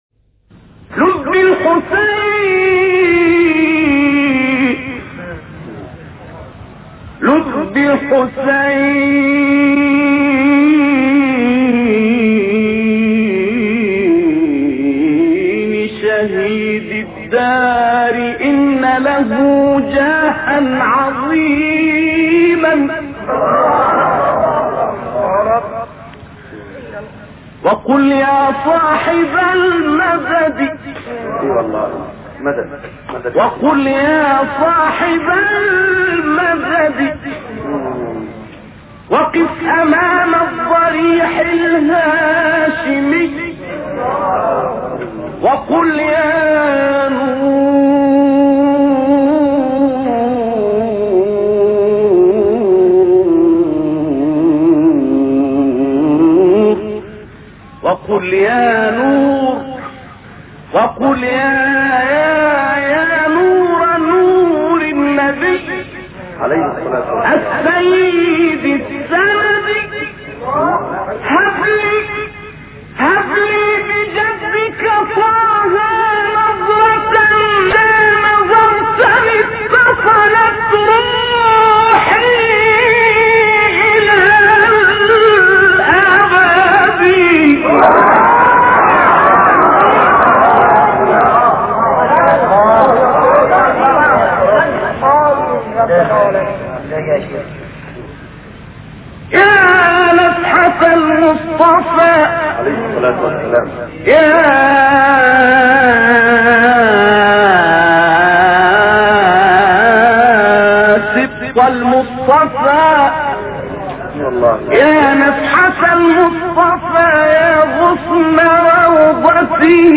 «لذ بالحسین» ابتهالی از سید نقشبندی
گروه فعالیت‌های قرآنی: ابتهال شنیدنی «لذ بالحسین» با صدای سید نقشبندی در رثای امام حسین(ع) ارائه می‌شود.
برچسب ها: خبرگزاری قرآن ، ایکنا ، فعالیت های قرآنی ، ابتهال ، سید محمد نقشبندی ، لذ بالحسین ، رثای امام حسین ، ابتهال محرم ، قاری ، مبتهل ، قرآن ، iqna